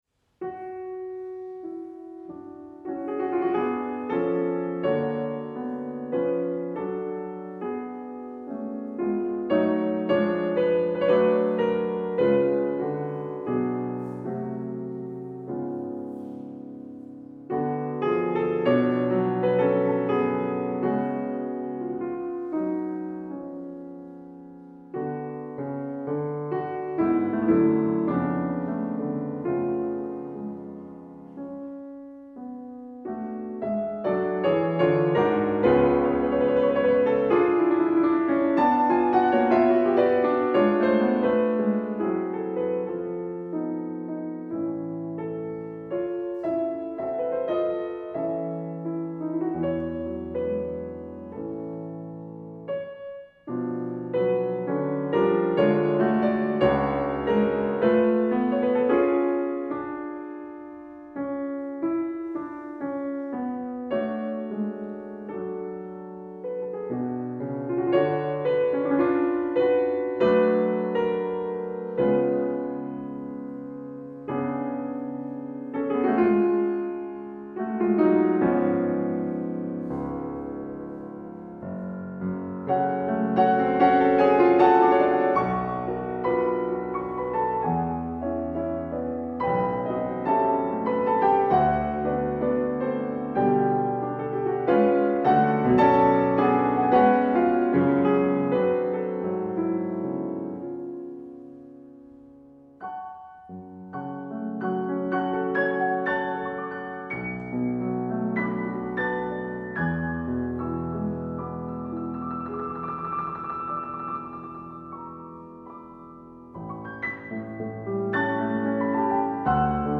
CICLE: Concerts de Cloenda